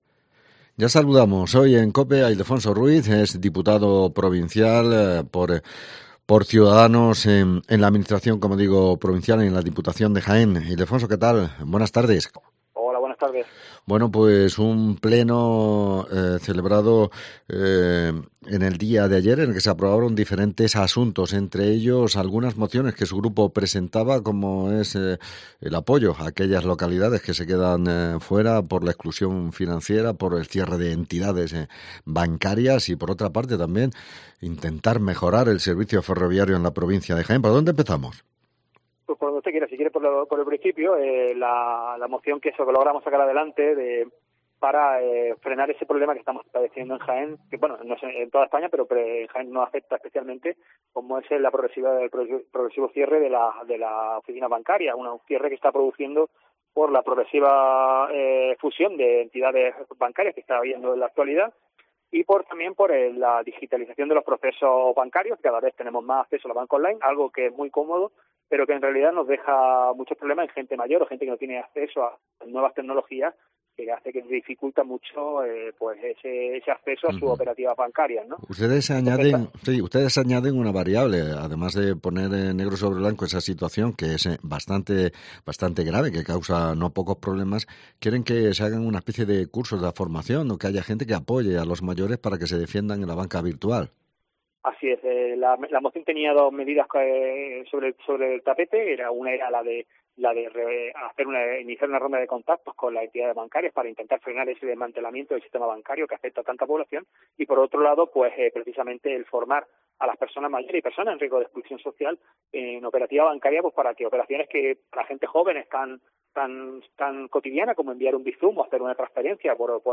Entrevista con Ildefonso Ruiz de Ciudadanos sobre exclusión financiera y tren